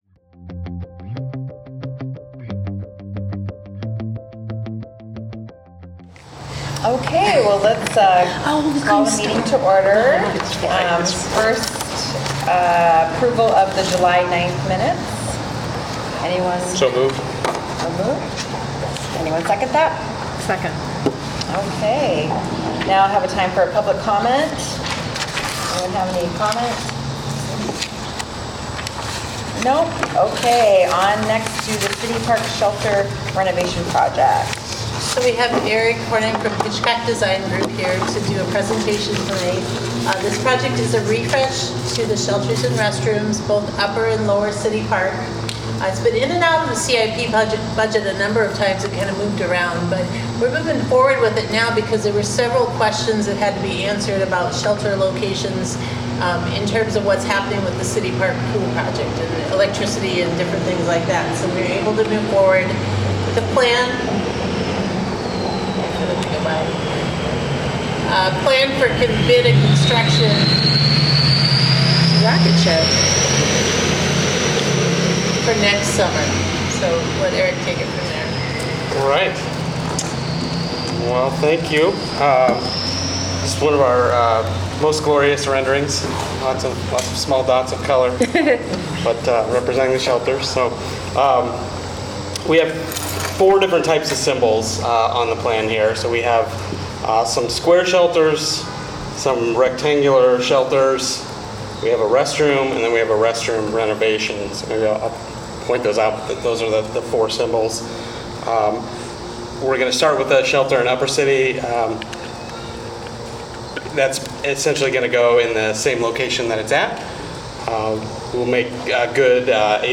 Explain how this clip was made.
A meeting of the City of Iowa City's Parks and Recreation Commission.